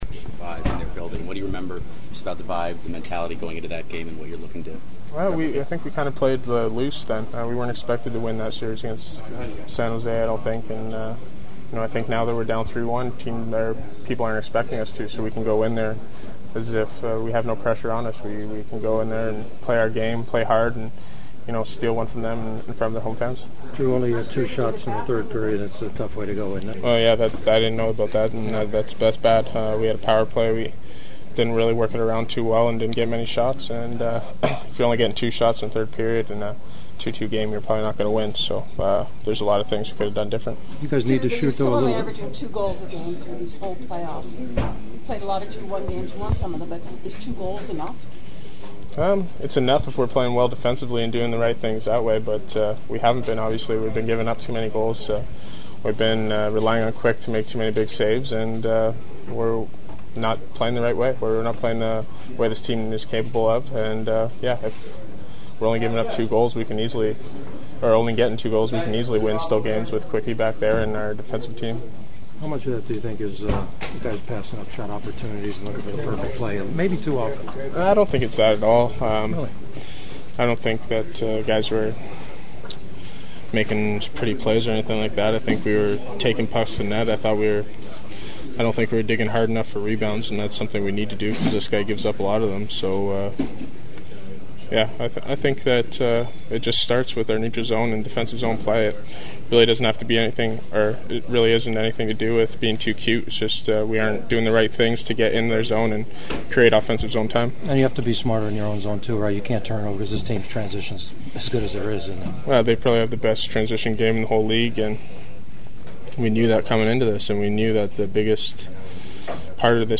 The following are my sounds of the postgame and this one was far from fun to get the losing side to have to comment on this game and what lies ahead…which immediately is a potential season-ending game 5 in Chicago on Saturday.
Kings defenseman Drew Doughty: